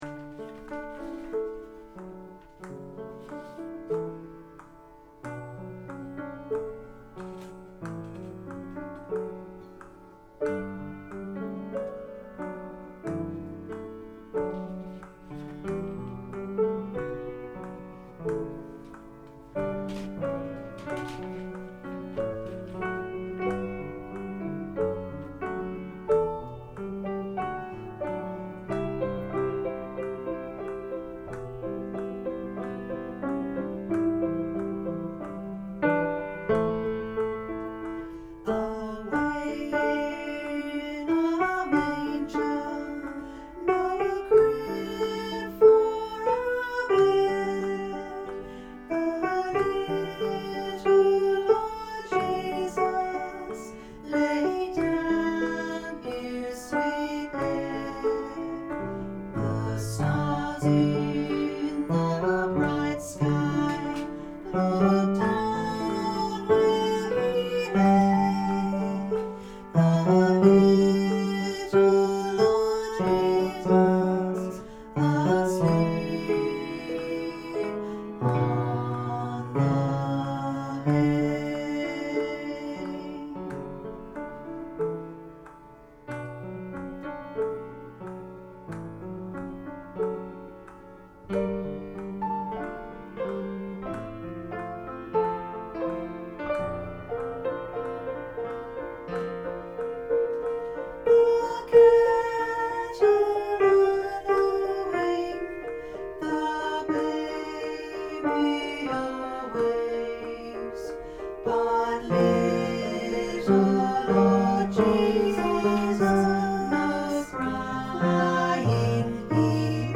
Vox Populi Choir is a community choir based in Carlton and open to all comers.